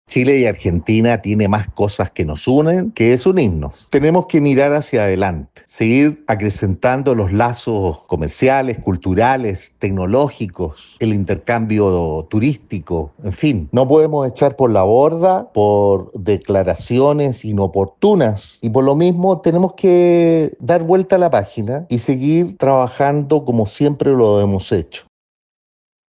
El diputado UDI, Cristhian Moreira, llamó a olvidar lo ocurrido y colaborar entre países para progresar, argumentando que con Argentina existen más similitudes que diferencias y que se debe “mirar hacia adelante”.